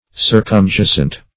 Search Result for " circumjacent" : The Collaborative International Dictionary of English v.0.48: Circumjacent \Cir`cum*ja"cent\, a. [L. circumjacens, p. pr. of circumjacere; circum + jac[=e]re to lie.] Lying round; bordering on every side.